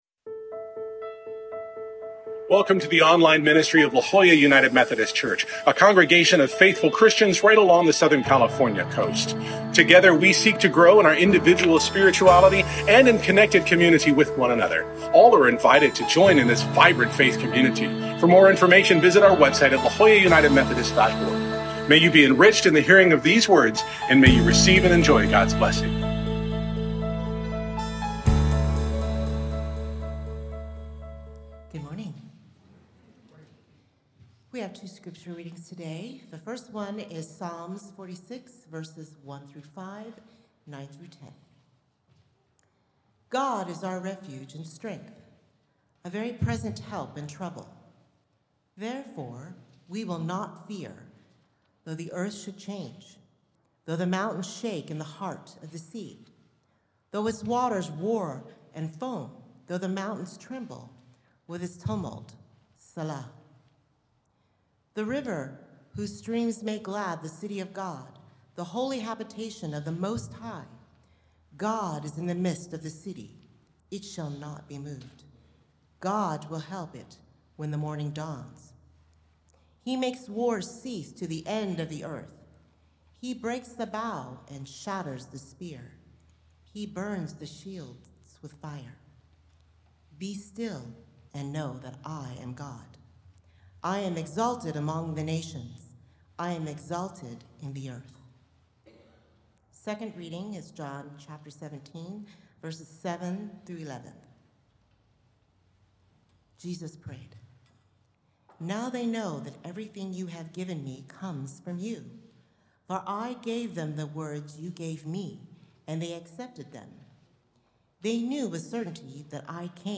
What is the power of words in an institution? This Ascension Sunday we conclude our post-Easter sermon series exploring “The Power of Words.”